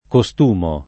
costumo [ ko S t 2 mo ]